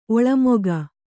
♪ oḷamoga